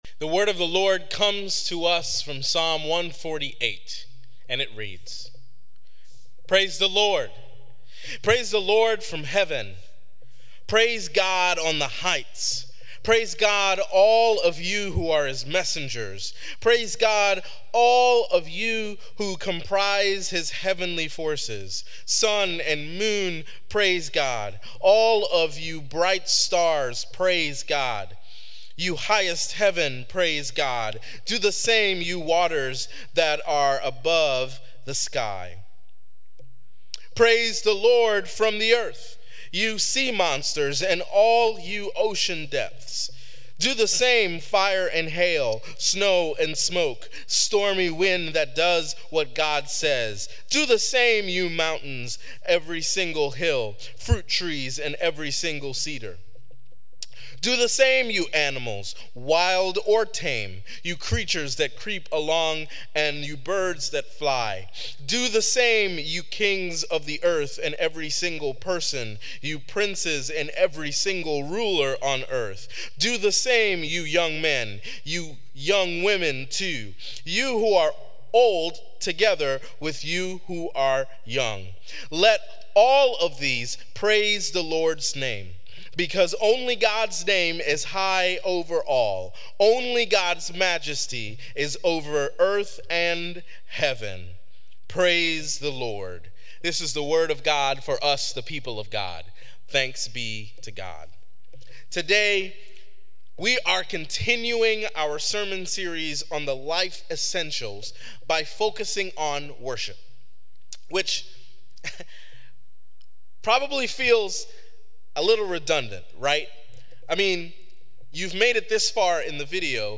This week, we explore Worship. Sermon Reflections: Understanding Worship : How do you define worship in your own life, and in what ways do you see it as a response to the revelation of God?